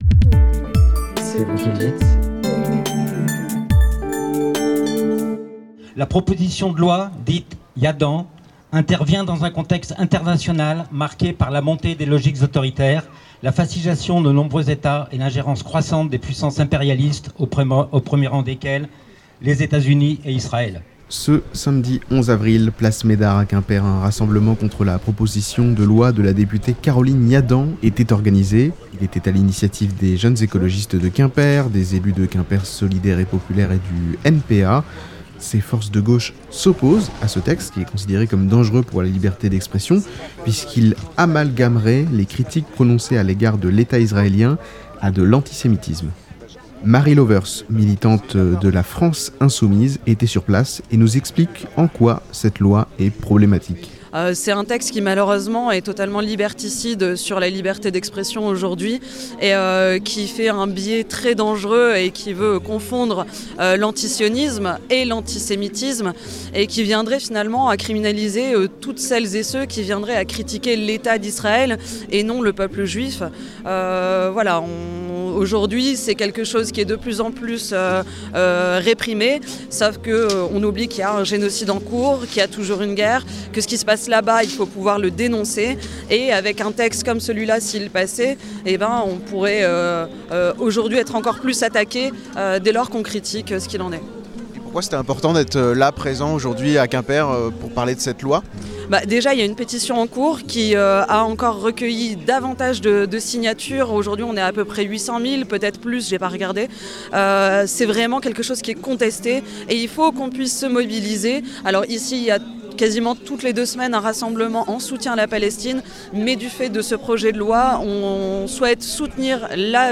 Samedi 11 avril 2026, place Médard à Quimper, un rassemblement contre la proposition de loi de la députée Caroline Yadan était organisé.